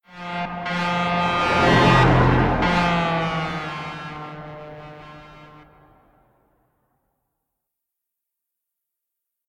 Truck Horn Passing By Left To Right